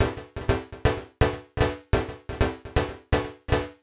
cch_synth_loop_scatter_125_Dm.wav